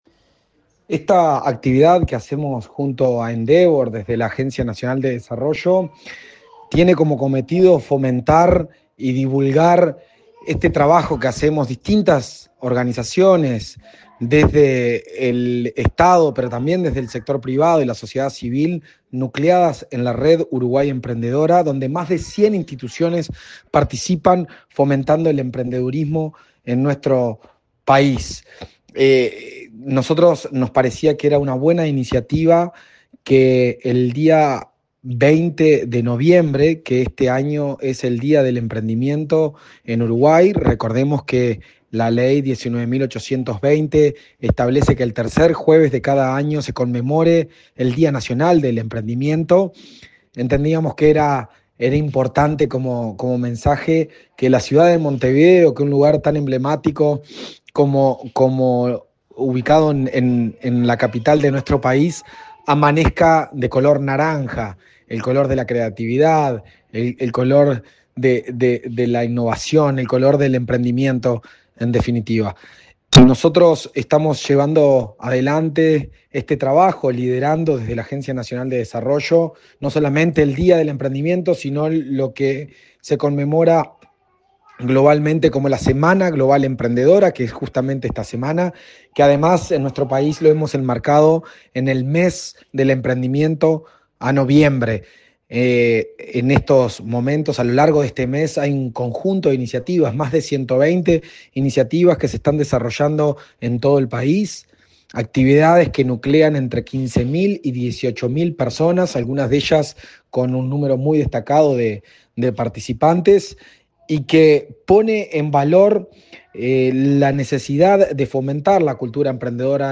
Declaraciones del presidente de ANDE. Juan Ignacio Dorrego
En ocasión de la intervención de las letras corpóreas de la capital, ubicadas en la Rambla República del Perú, por el Día Nacional de la Cultura